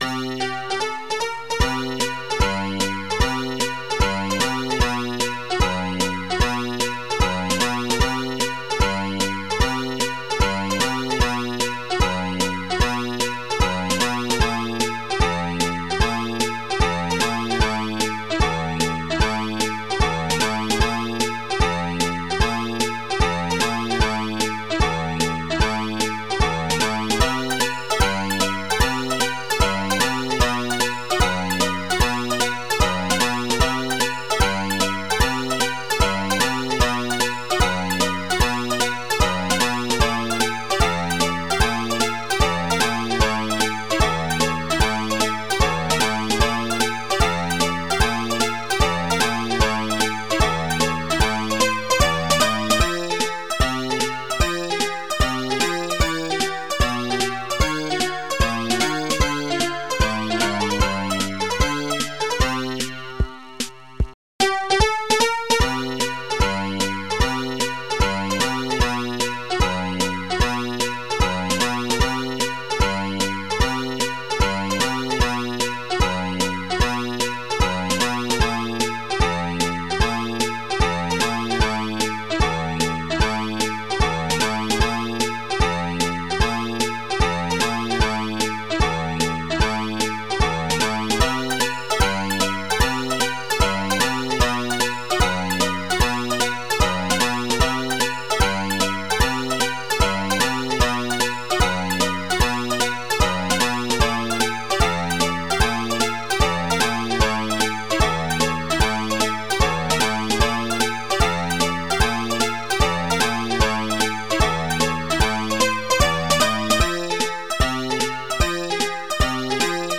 Protracker Module
tr-808 snaredrum tr-808 bassdrum